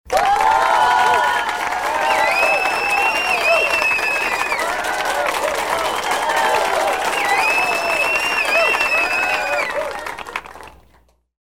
Aplausos Mañanas Magicas Sound Buttons